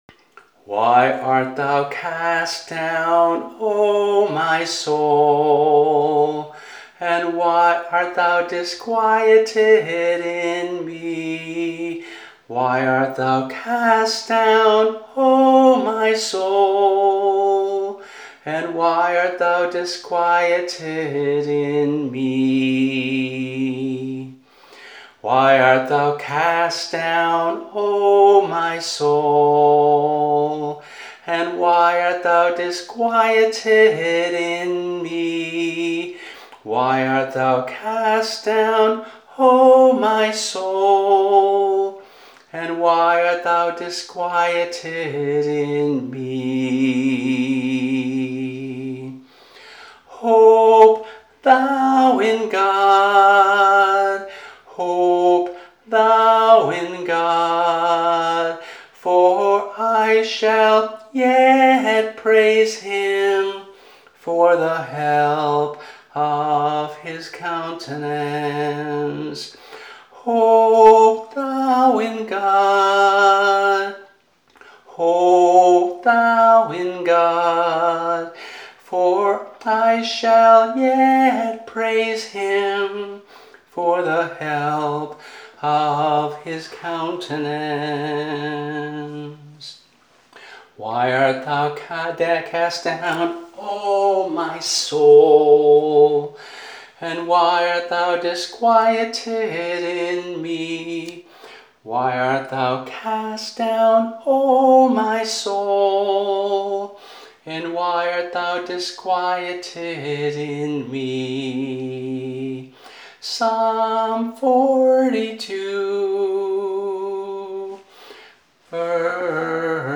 [MP3 - voice only]